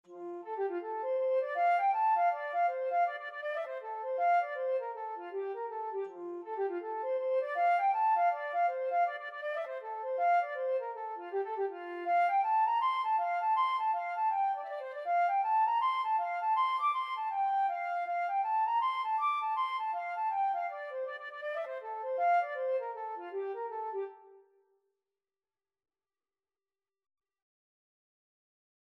Flute version
4/4 (View more 4/4 Music)
F5-D7
Flute  (View more Easy Flute Music)